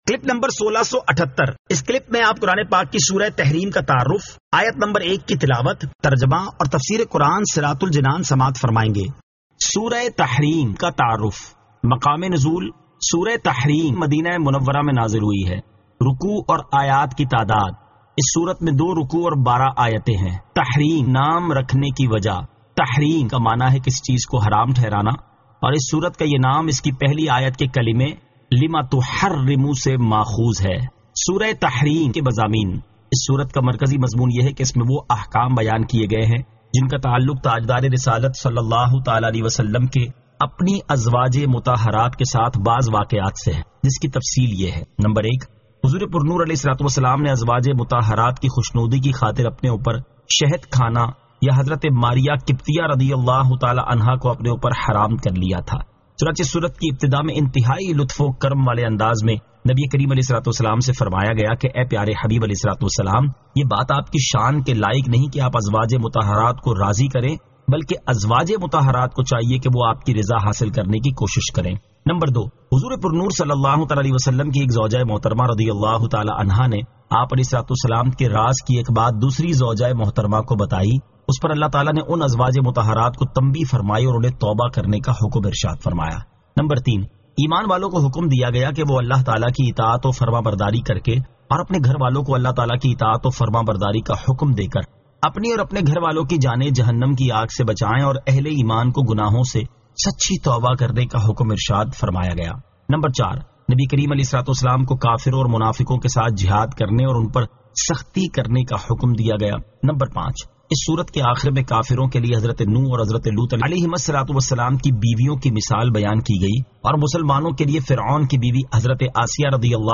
Surah At-Tahrim 01 To 01 Tilawat , Tarjama , Tafseer